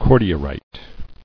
[cor·di·er·ite]